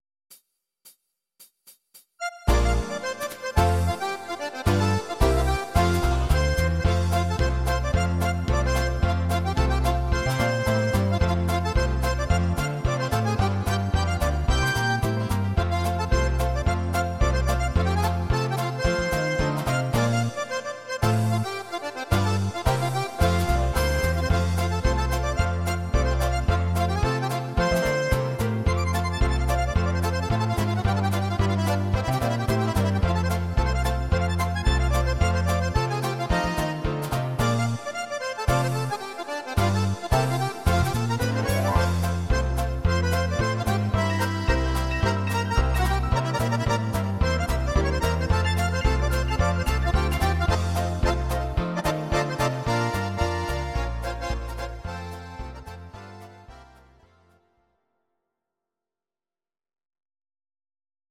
These are MP3 versions of our MIDI file catalogue.
Akkordeon